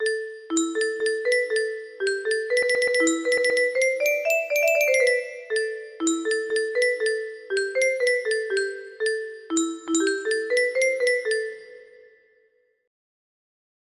Dans les prisons de Nantes music box melody
Wow! It seems like this melody can be played offline on a 15 note paper strip music box!